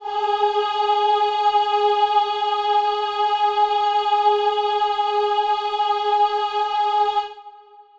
Choir Piano (Wav)
G#4.wav